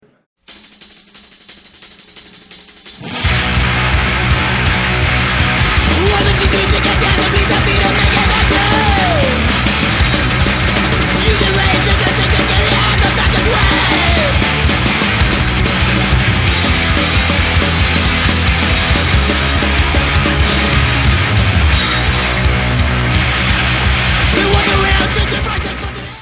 hardcore music